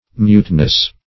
Muteness \Mute"ness\, n.